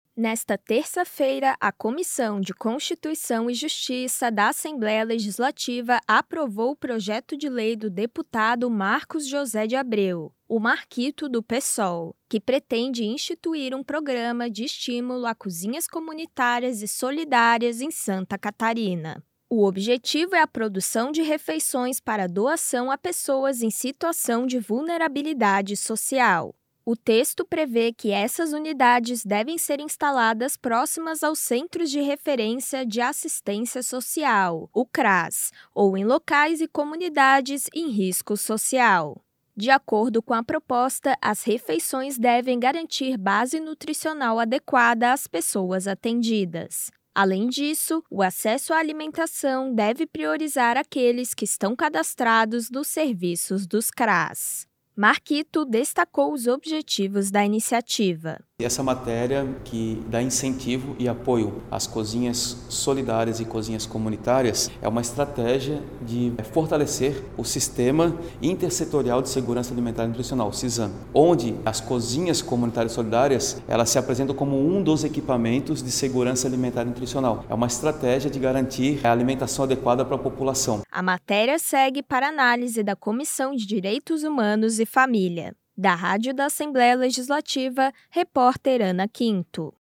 Entrevista com:
- deputado Marcos José de Abreu - Marquito (Psol), autor do projeto de lei.